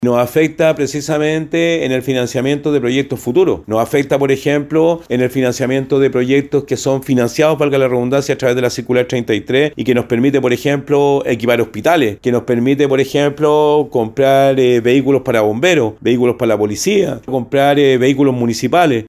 En la región de Valparaíso, el recorte sería de aproximadamente $5.500 millones de pesos, lo que impactaría directamente en la adquisición de recursos urgentes para salud y seguridad. Así lo detalló el gobernador regional, Rodrigo Mundaca.